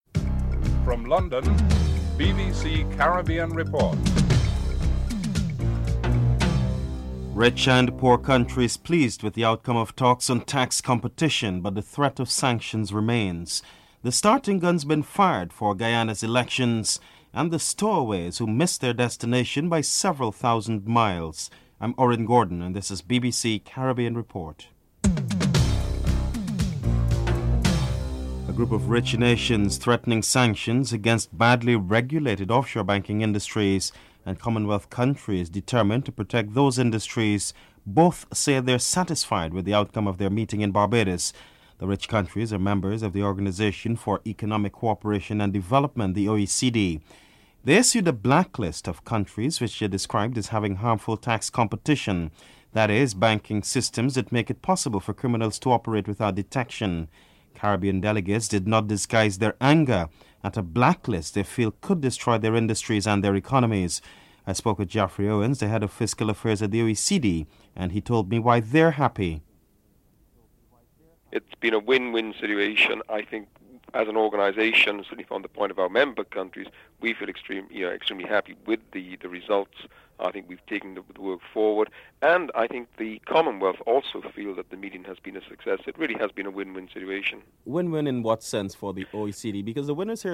Secretary General of the People's Progressive Party is interviewed.